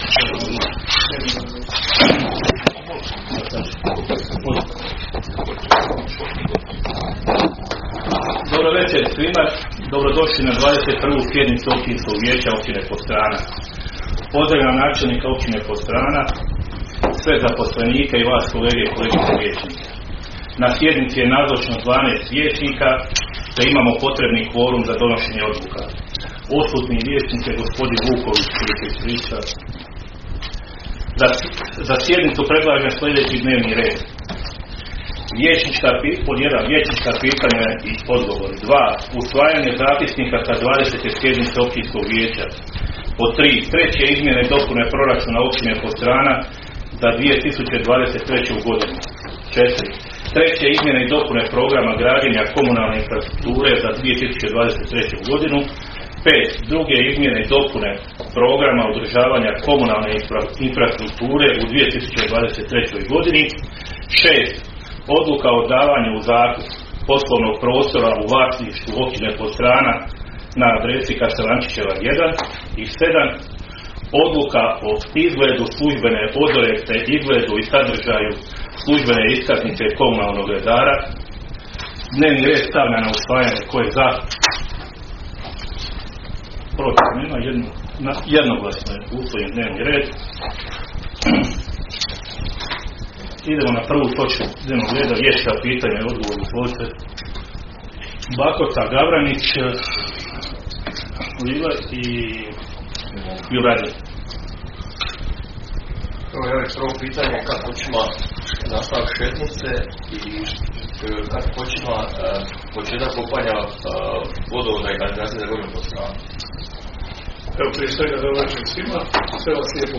Sjednica će se održati dana 25. listopada (srijeda) 2023. godine u 19,00 sati u vijećnici Općine Podstrana.